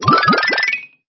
se4_p_mario_3d2d_change1.mp3